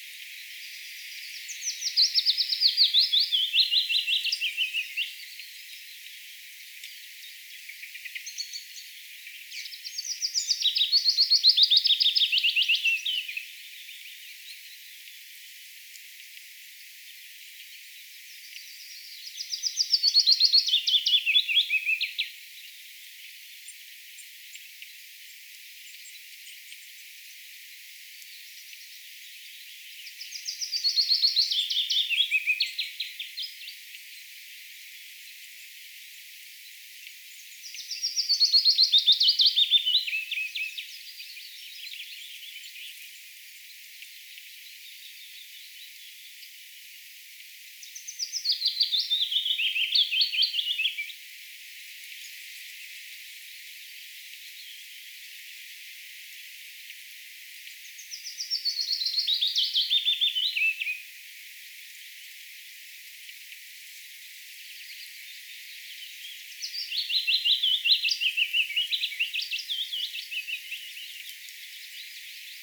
pajulinnun laulua
pajulinnun_laulua.mp3